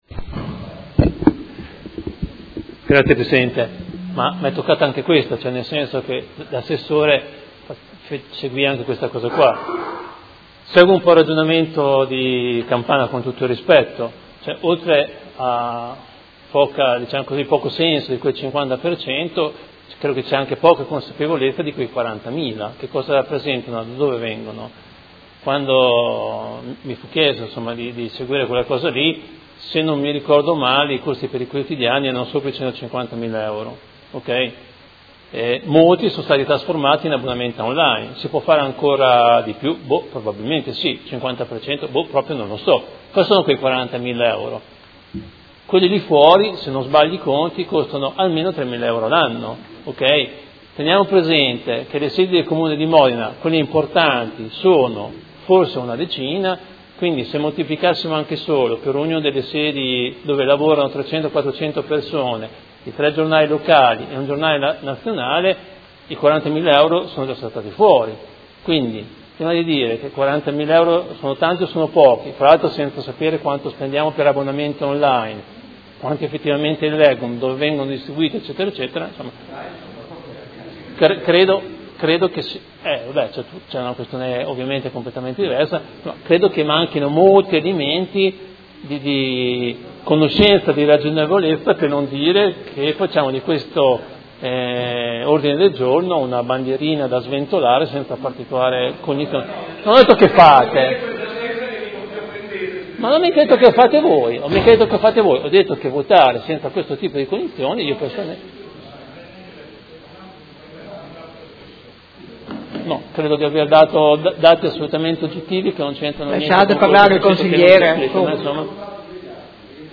Dibattito su Mozione presentata dal Gruppo Movimento Cinque Stelle avente per oggetto: Spese quotidiani presso edicole